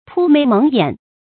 铺眉蒙眼 pū méi méng yǎn
铺眉蒙眼发音